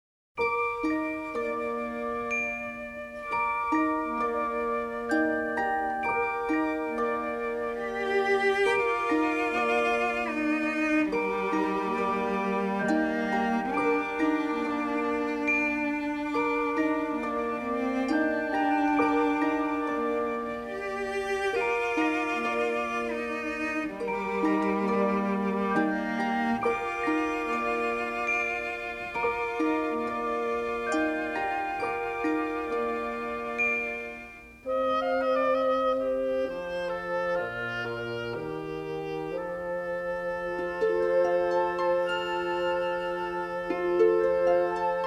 each filled with exotic melody and instrumentation.
galloping passages
with saxophone, harpsichord and flute elegantly spotlighted.
remixed in stereo from the original three-track masters.